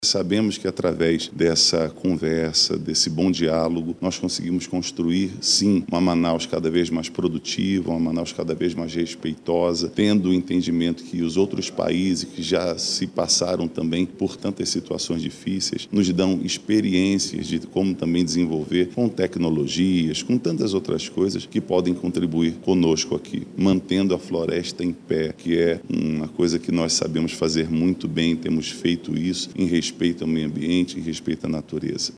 O presidente da 24ª Comissão de Turismo e Relações Internacionais da CMM, o vereador João Carlos, do Republicanos, disse que a visita cria um ambiente de boas oportunidades para a capital amazonense.